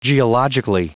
Prononciation du mot geologically en anglais (fichier audio)
Prononciation du mot : geologically